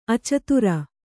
♪ acatura